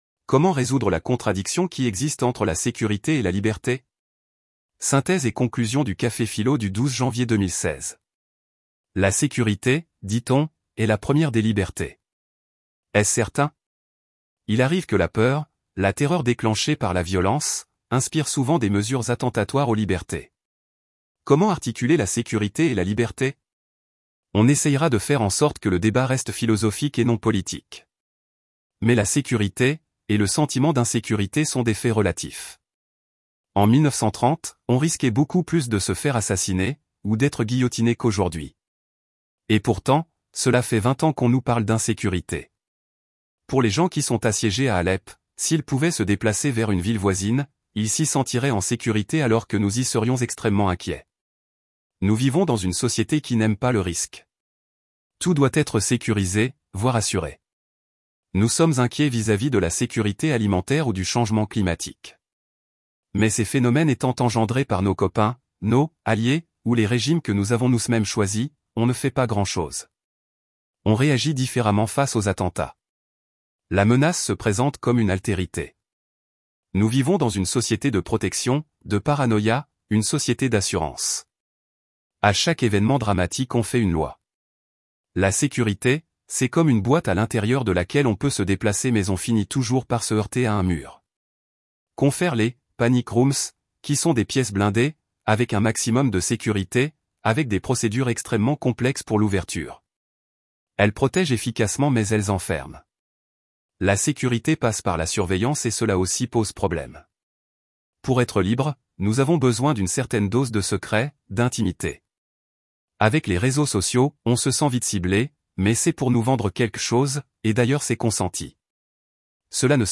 Synthèse et conclusion du café-philo du 12 Janvier 2016